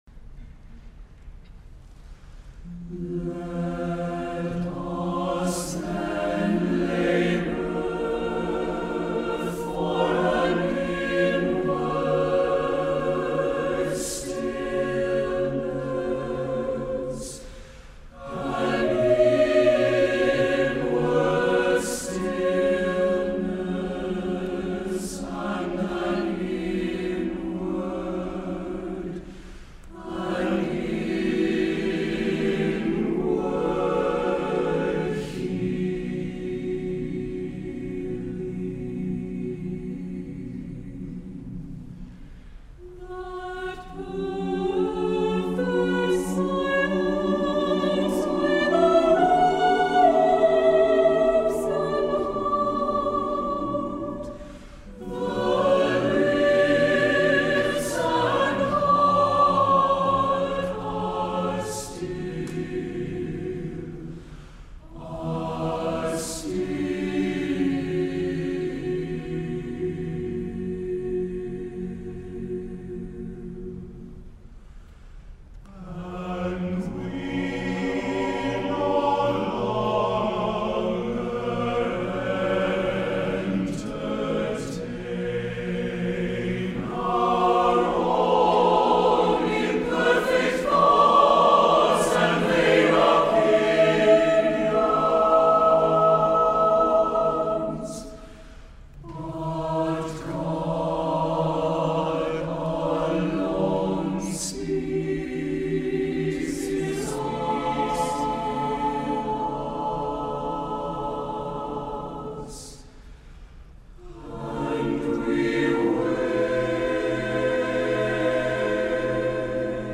Voicing: SATB divisi